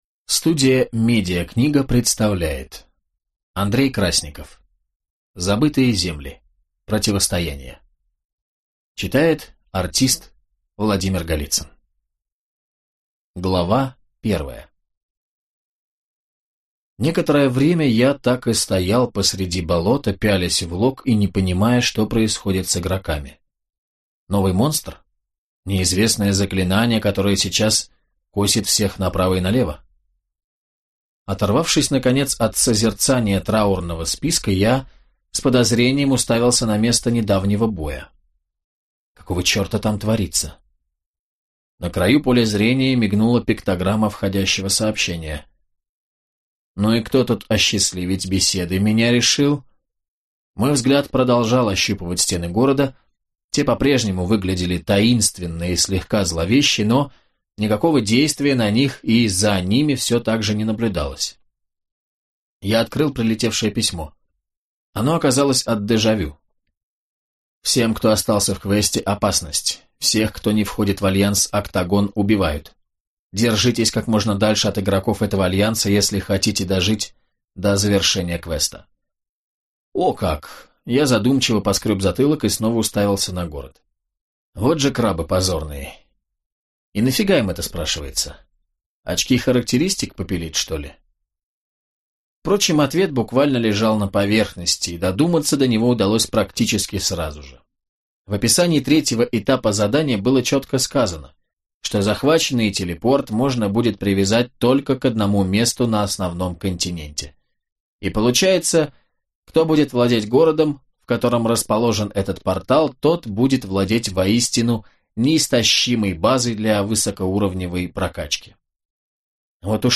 Aудиокнига Противостояние